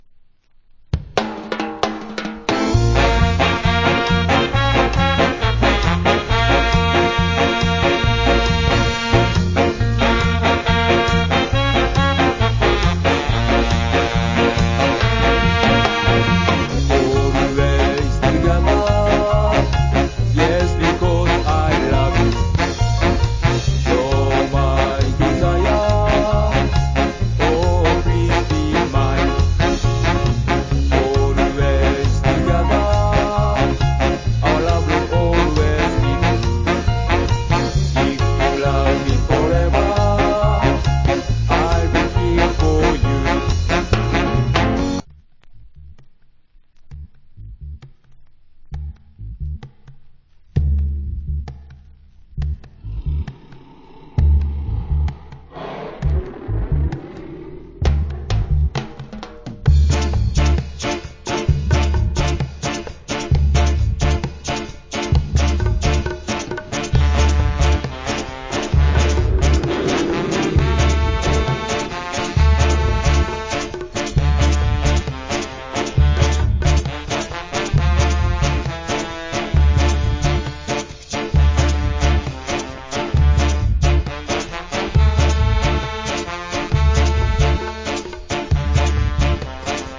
category Ska